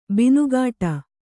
♪ binugāṭa